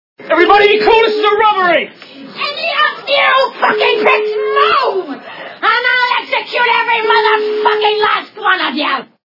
» Звуки » Из фильмов и телепередач » Pumpkin Honey Bunny - Sample